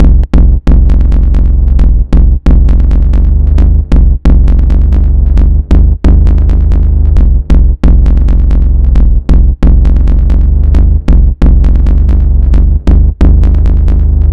• HardGroove - Techno Pitch Bend Bass (7) - Fm - 134.wav
HardGroove_-_Techno_Pitch_Bend_Bass_(7)_-_Fm_-_134_ddA.wav